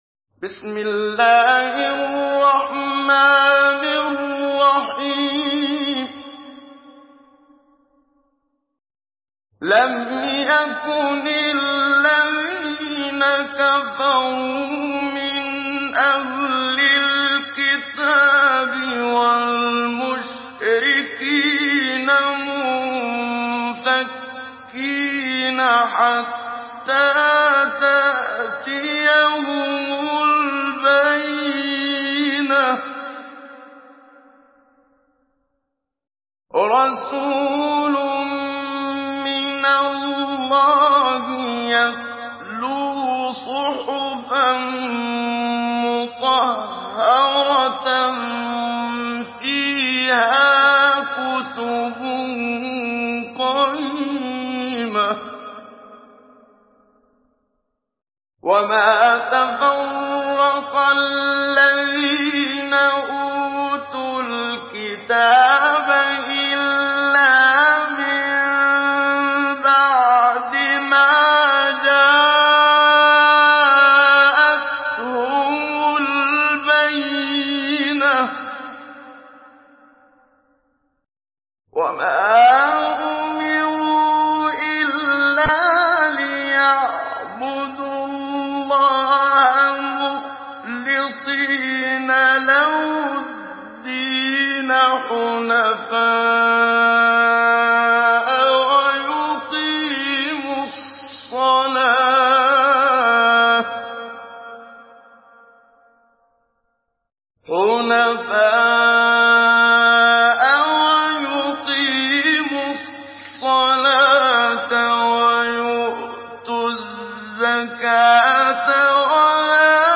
Surah Al-Bayyinah MP3 in the Voice of Muhammad Siddiq Minshawi Mujawwad in Hafs Narration